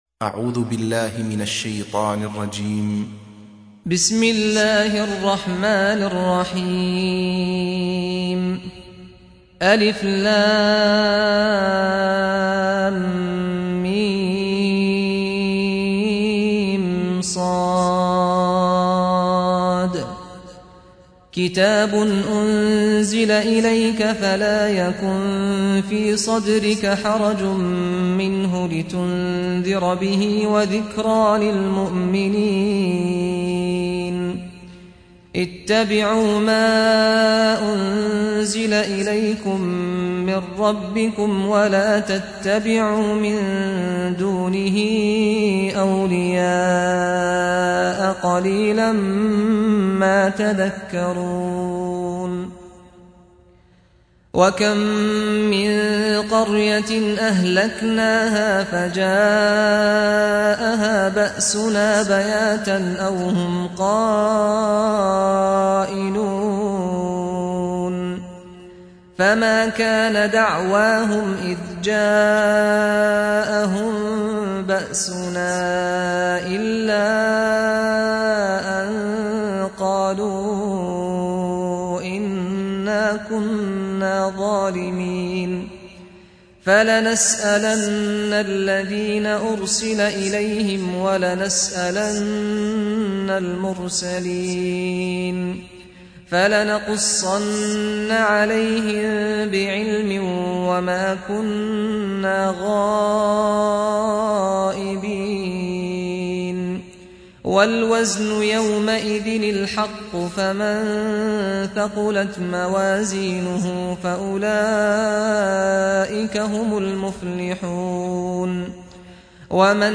سُورَةُ الأَعۡرَافِ بصوت الشيخ سعد الغامدي